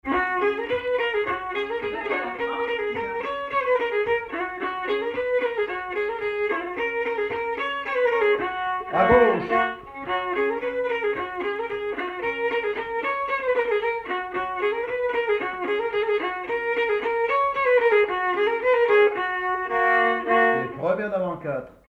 danse : quadrille
circonstance : bal, dancerie
Pièce musicale inédite